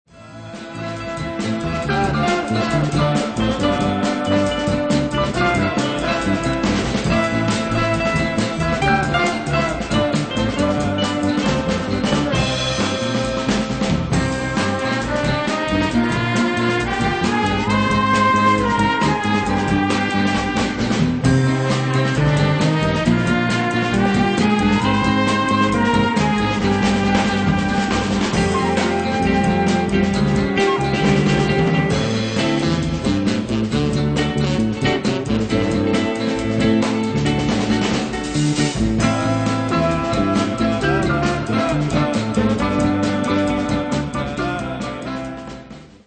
Zwei Soundtracks zu deutschen Aufklärungsfilmen (1968/69)